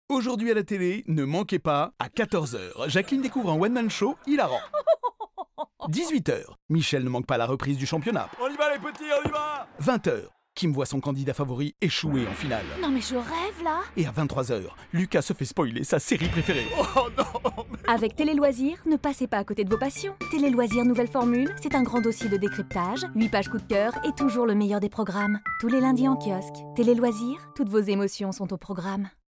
Pub Radio TELELOISIRS |
Publicité radio pour le programme TV TéléLoisirs, toutes les voix féminines!